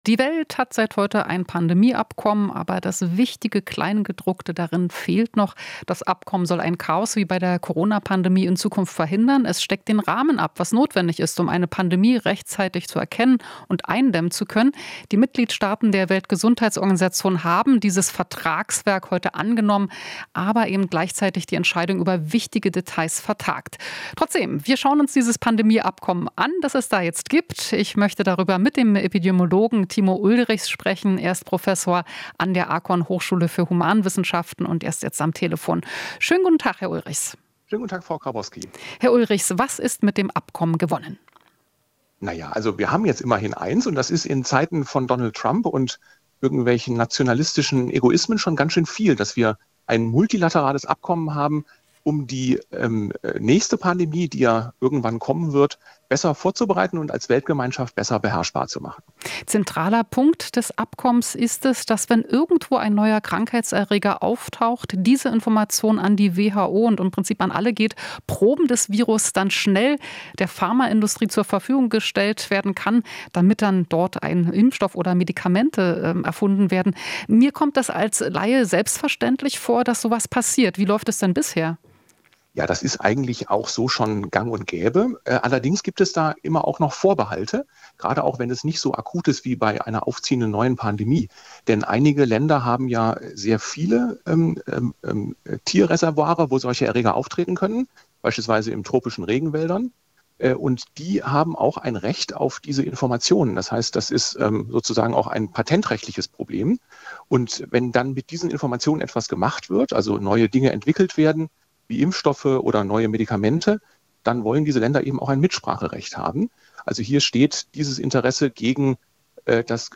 Interview - Epidemiologe: "Nach der Pandemie ist vor der Pandemie"